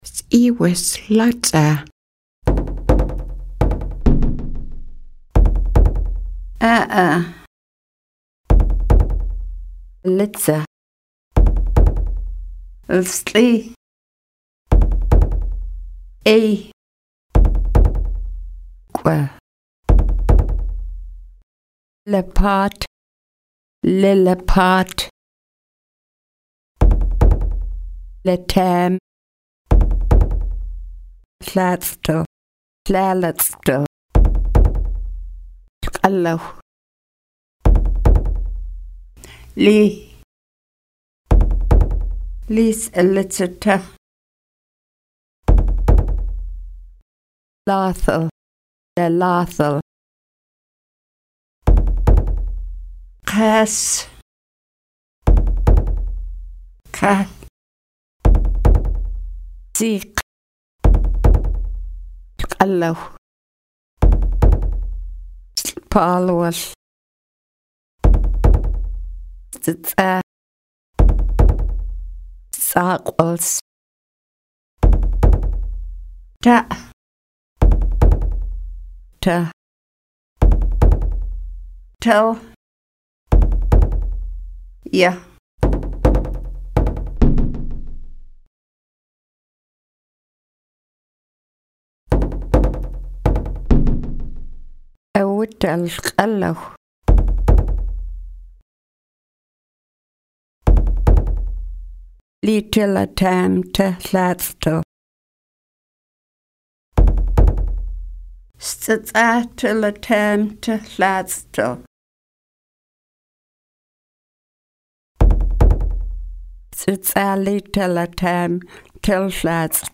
Listen to the elder
Full Lesson Audio-Vocabulary and Dialogues